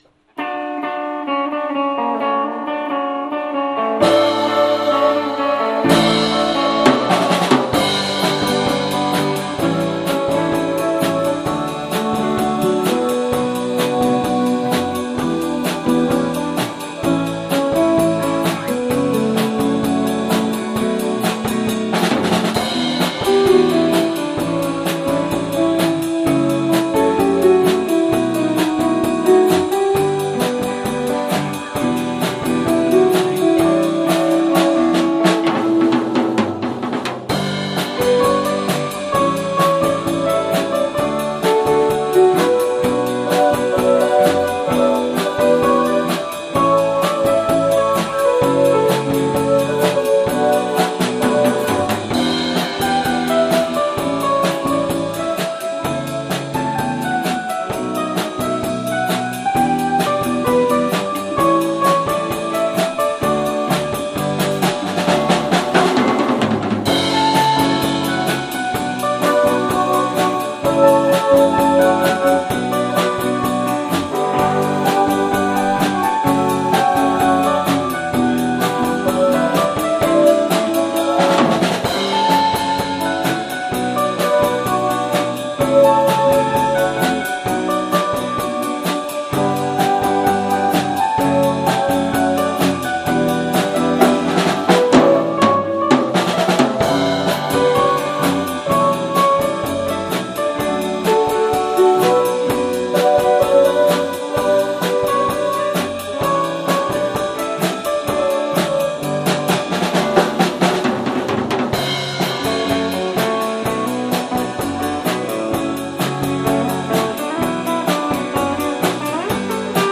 2019 Summer Live